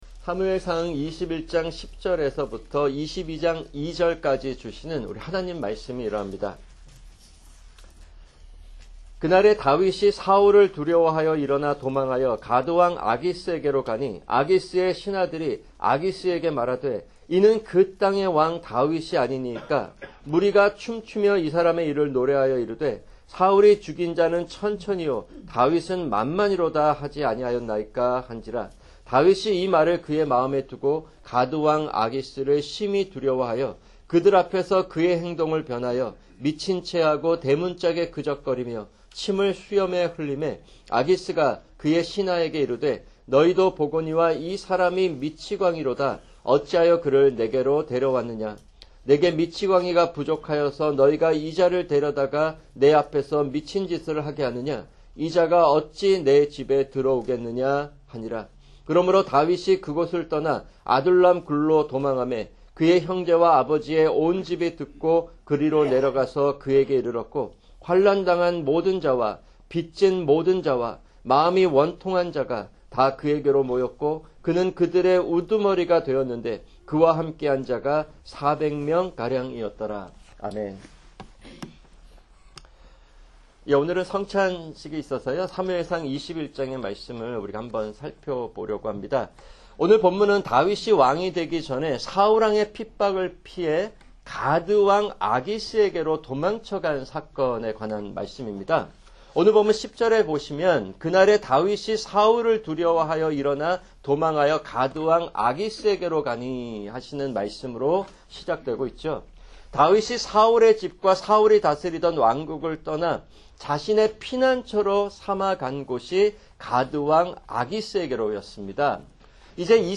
[주일 설교] 사무엘상 21:10-22:2